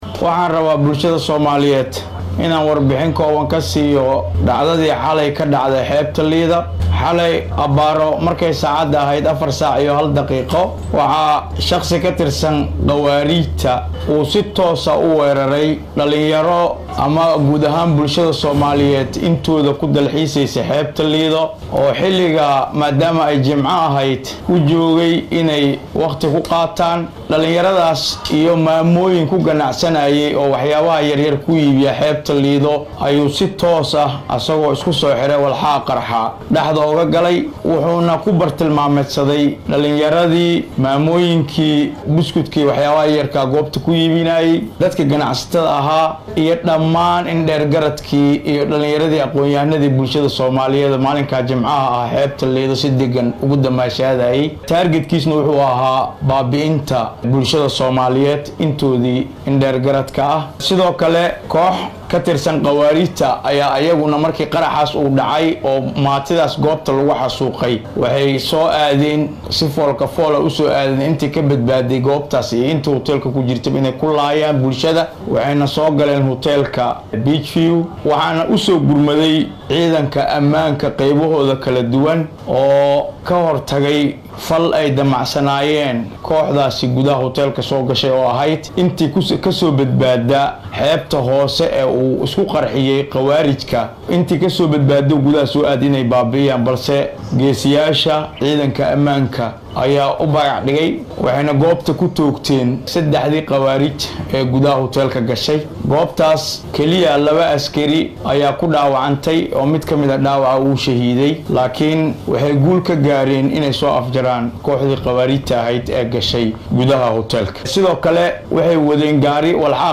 shir jaraa’id u qabtay wariyeyaasha